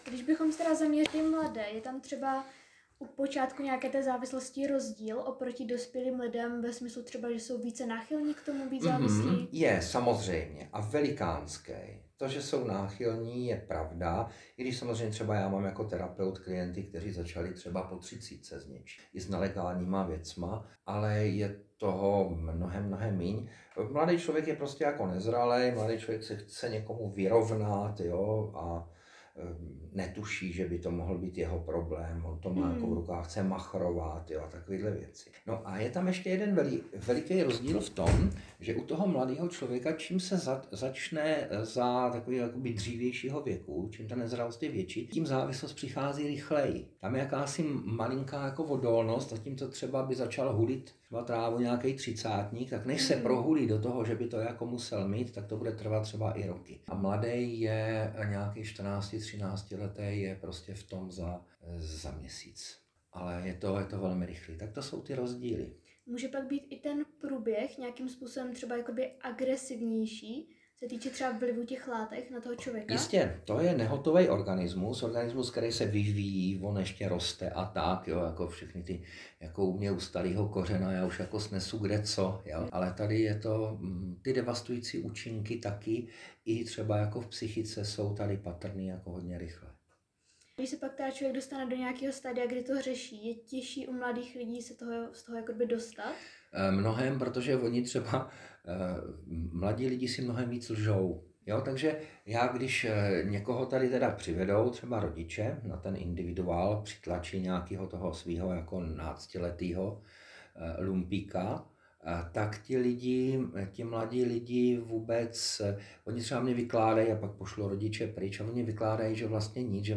Rozhovory